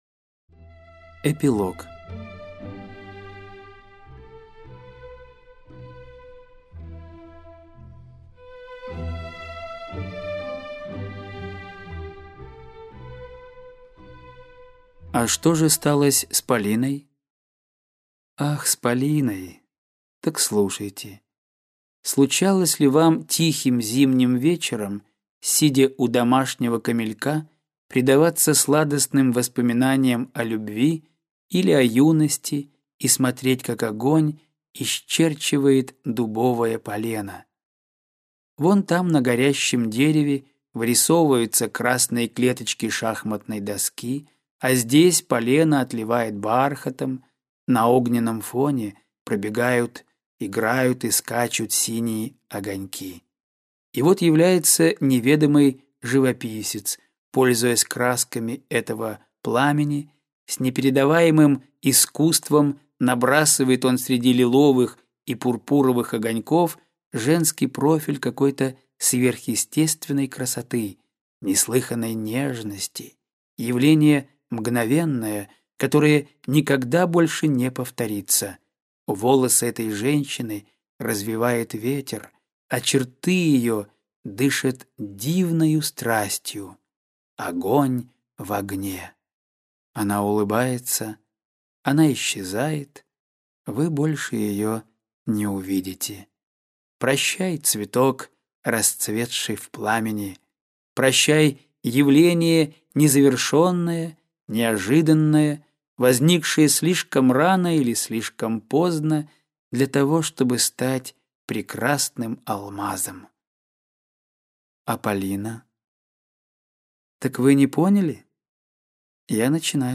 Скачать, слушать онлайн аудиокнигу Шагреневая кожа из жанра Классическая проза